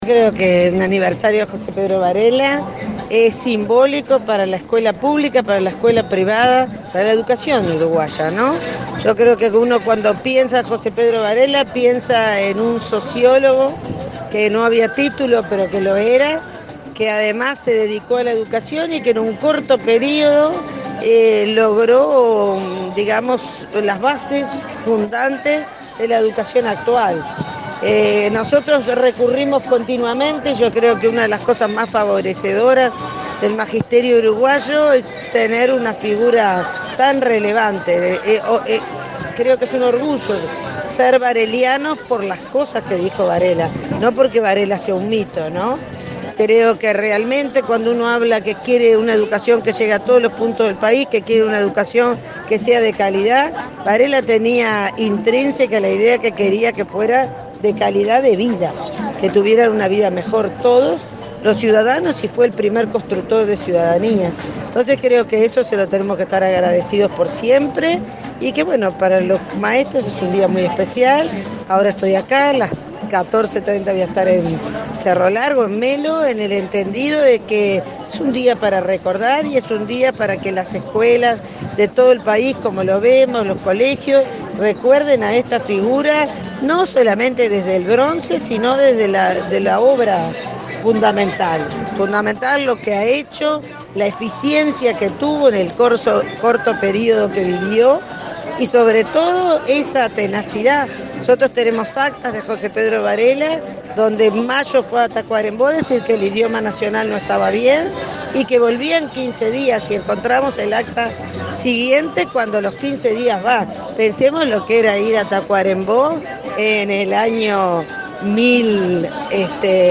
En el marco del acto que recordó la figura de José Pedro Varela en el 174.° aniversario de su nacimiento, la directora de Primaria, Irupé Buzzetti, subrayó a la prensa que se prevé a fin de este mes la apertura de nuevos grupos de nivel 3 años, ya que hay 500 niños en lista de espera.